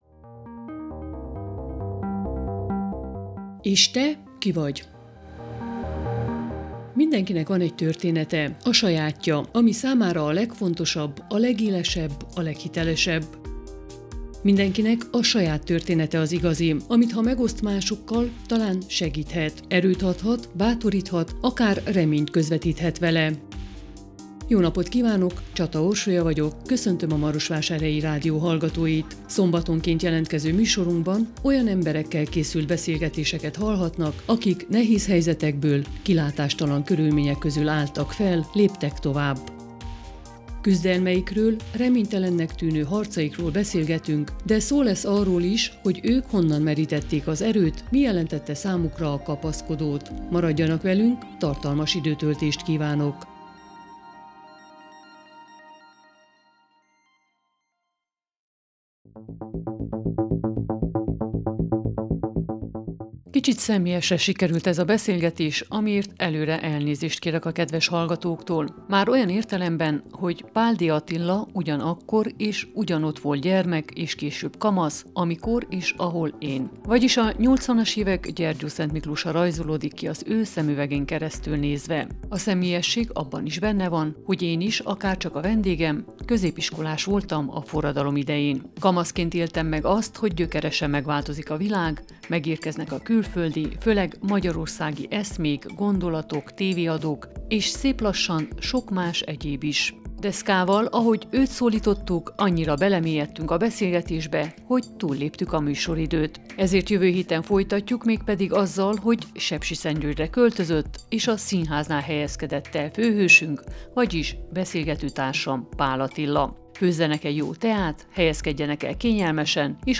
Kicsit személyesre sikerült ez a beszélgetés, amiért előre elnézést kérek a kedves hallgatóktól.